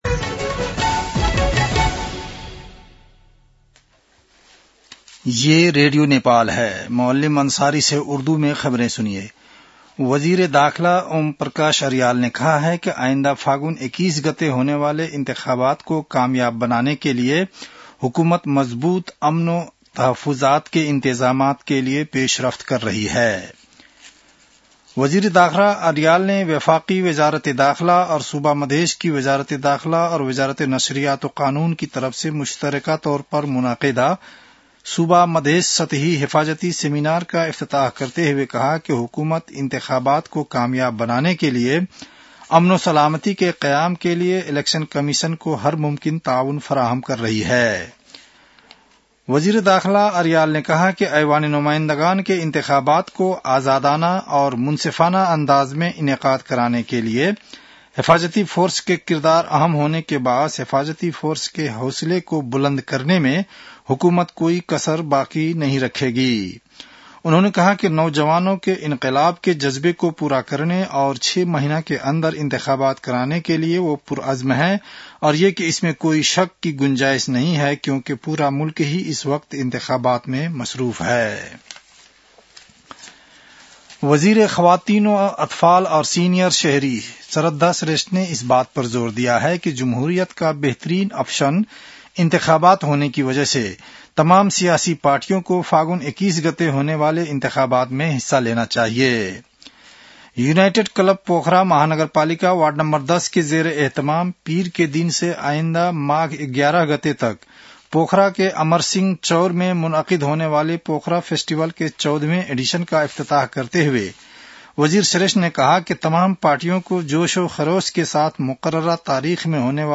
उर्दु भाषामा समाचार : ३० पुष , २०८२
Urdu-news-9-30.mp3